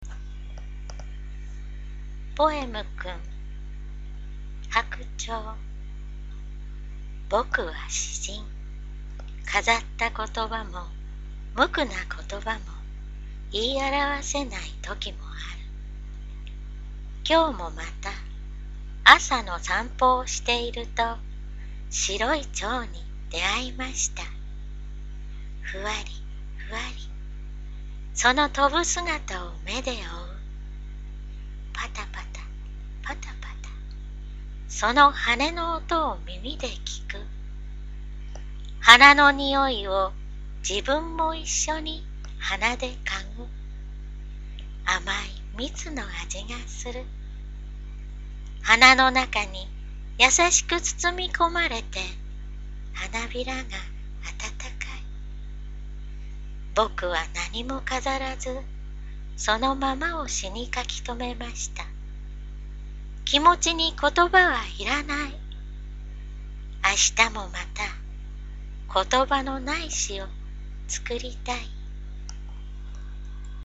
朗読